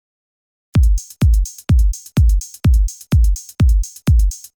Alle Soundbeispiele aus diesem Elektrobeat Tutorial stammen aus dem Korg Kronos 2.
2. HiHat
Die HiHat soll auf den Sechzehnteln laufen.
Die 16tel treiben den Beat nach vorne und verpassen ihm einen ordentlichen Drive.
Daher suche ich eine etwas dünnere HiHat mit viel Attack aus.
03__hihat__beats_bauen_synthesizer.mp3